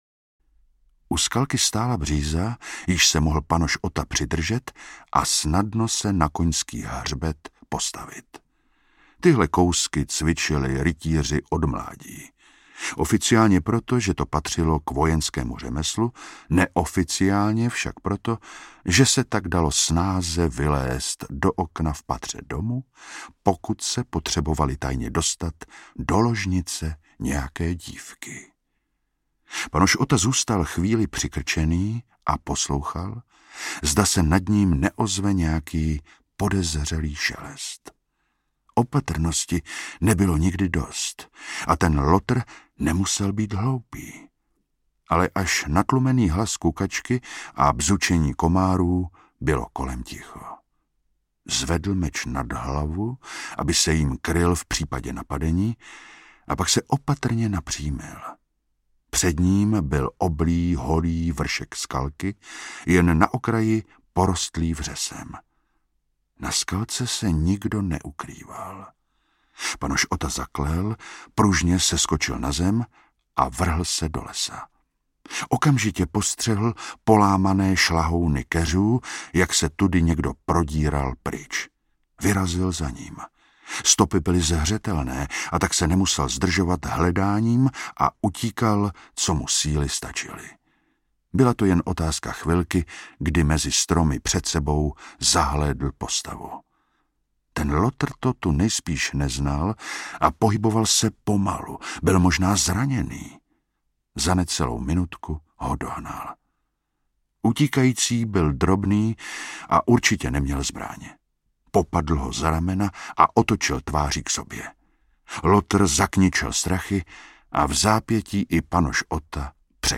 Anděl smrti audiokniha
Ukázka z knihy
| Vyrobilo studio Soundguru.